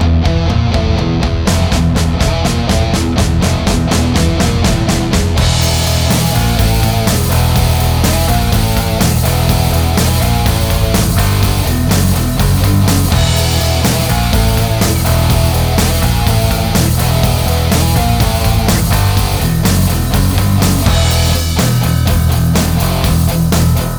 Minus Solo Guitar Rock 5:31 Buy £1.50